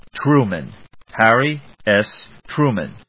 Tru・man /trúːmən/
• / trúːmən(米国英語)